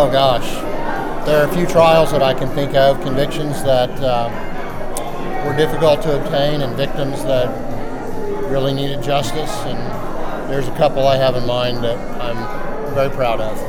District 11 District Attorney Kevin Buchanan held his retirement reception yesterday evening at the Bartlesville Community Center.
Here is Buchanan on what some of his highlights are from his 12-year career.